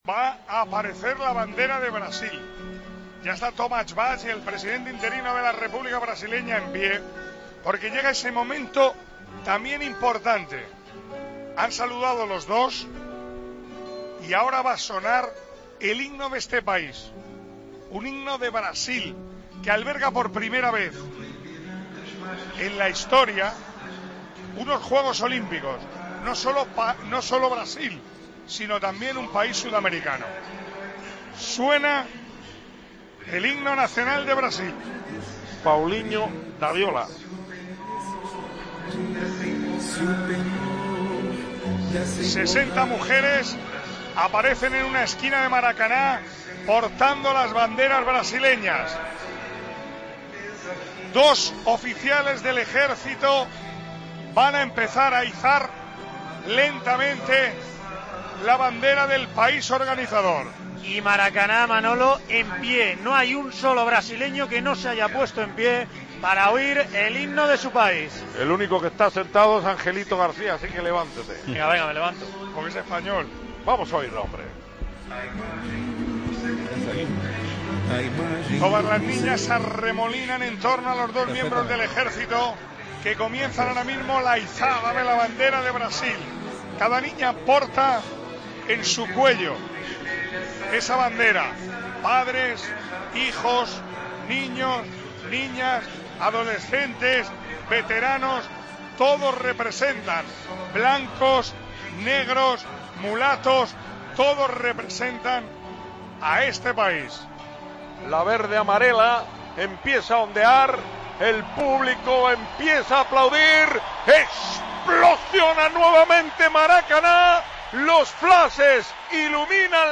Momento del himno de Brasil, en la ceremonia de inauguración